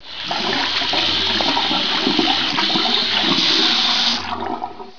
Folder: water